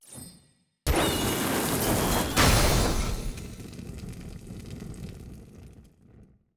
sfx-tft-skilltree-ceremony-fire-crest-up.ogg